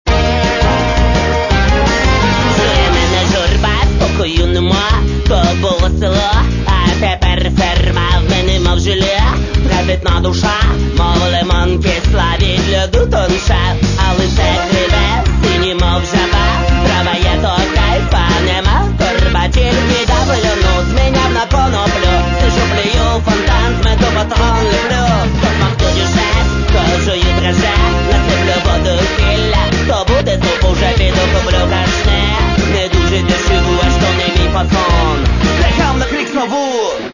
Каталог -> Хип-хоп